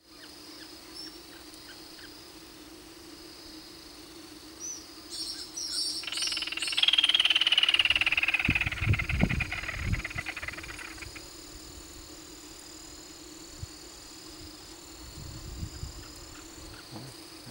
Burrito Pecho Gris (Laterallus exilis)
Nombre en inglés: Grey-breasted Crake
Fase de la vida: Adulto
Localidad o área protegida: Capanema
Condición: Silvestre
Certeza: Vocalización Grabada